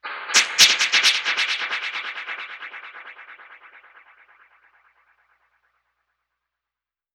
Index of /musicradar/dub-percussion-samples/134bpm
DPFX_PercHit_B_134-03.wav